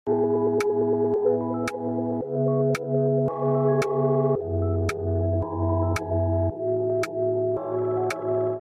we make broken fax machine sound effects free download
we make broken fax machine sounds 📠🫡